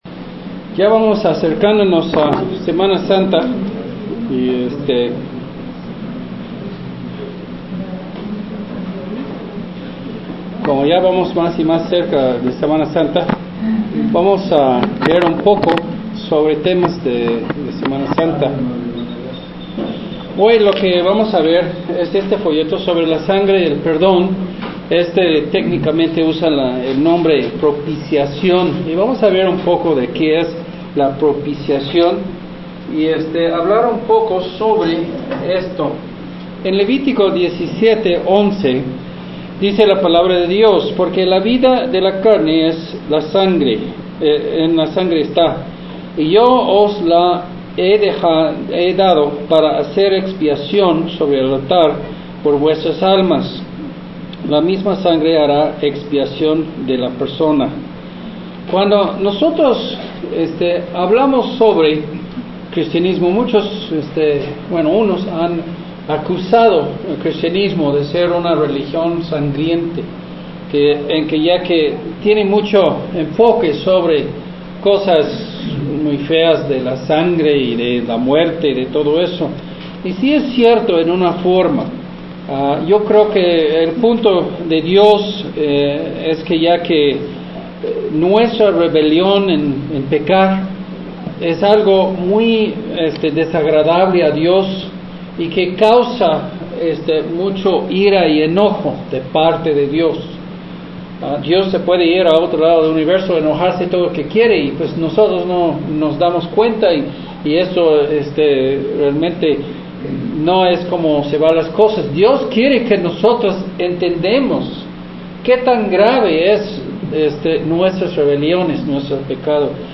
Un sermón sobre como la sangre de Jesús nos da el perdón de nuestros pecados.
Sermón de audio